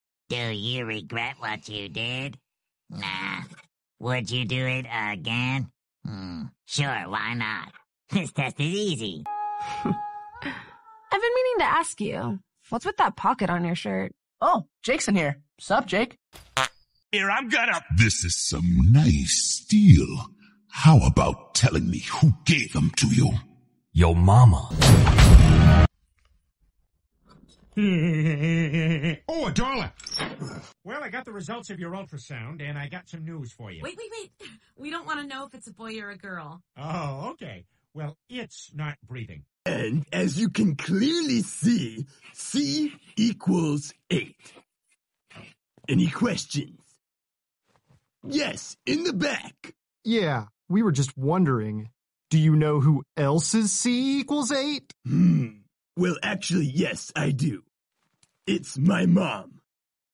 Top 5 funniest cartoon moments sound effects free download
You Just Search Sound Effects And Download. tiktok funny sound hahaha Download Sound Effect Home